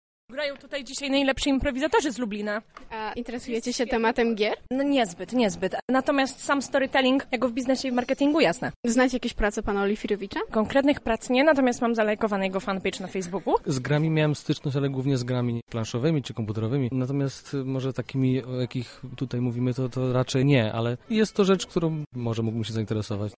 Nasza reporterka zapytała widownię o to, co skłoniło ich do udziału w wydarzeniu:
widownia.mp3